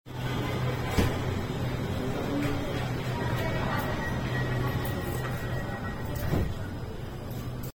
LRT 1 4th Generation (LRTA Class sound effects free download
LRT-1 4th Generation (LRTA Class 13000) Doors Closing Sound.